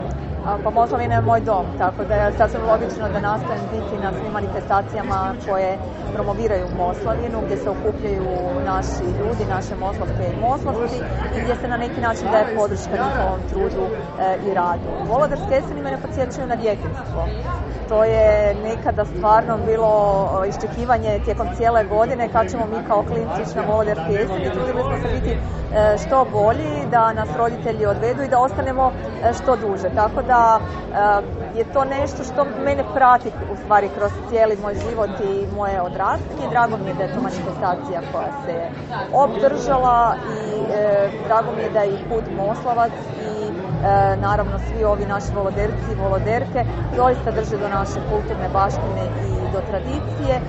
Saborska zastupnica i predsjednica saborskog Odbora za poljoprivredu Marijana Petir još od djetinjstva, ne propušta Volodersku jesen